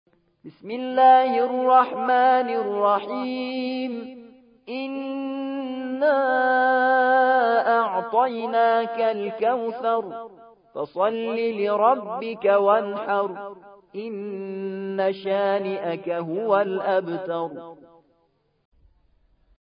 108. سورة الكوثر / القارئ